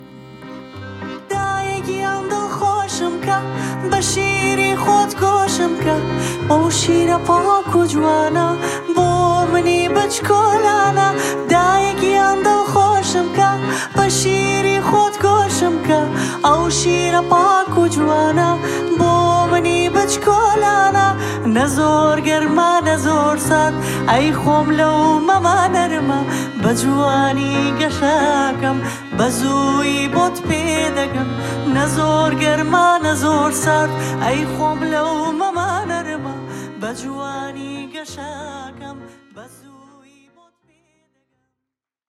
Fragment uit de podcast aflevering 'Borst Vooruit'
zangeres
accordeon